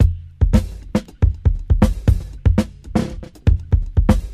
• 111 Bpm Fresh Drum Loop F Key.wav
Free drum beat - kick tuned to the F note. Loudest frequency: 272Hz
111-bpm-fresh-drum-loop-f-key-C1y.wav